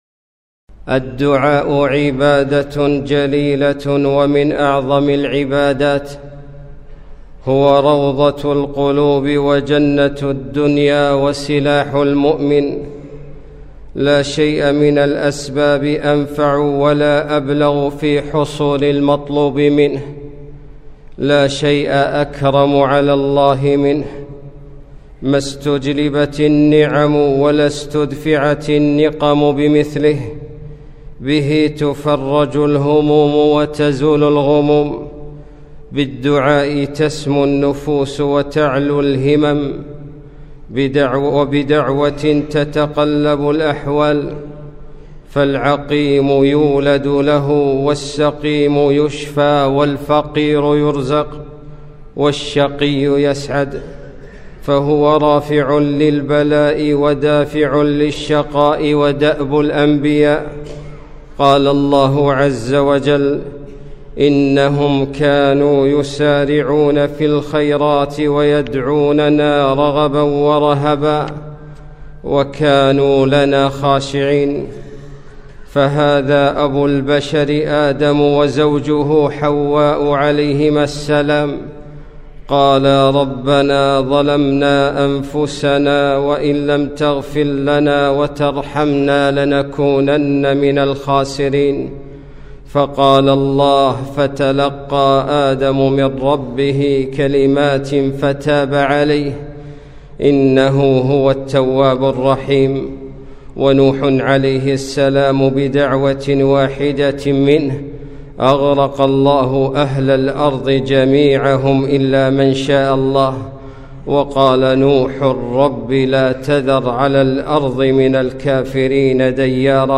خطبة - الدعاء